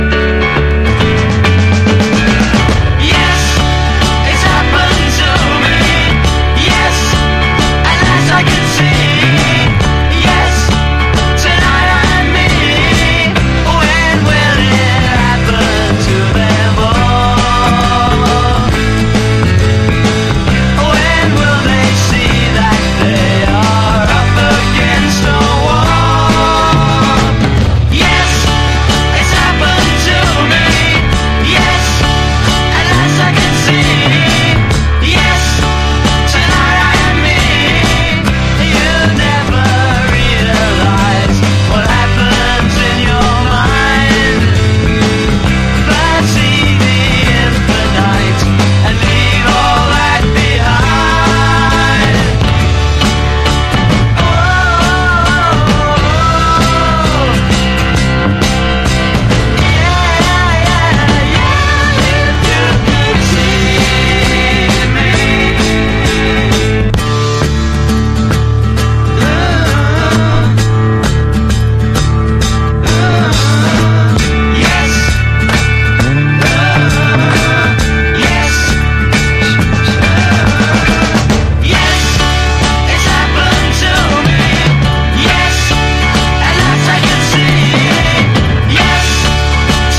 中期ビートルズ並みのポップさとメロ力、ブリティッシュビートの勢いが合体。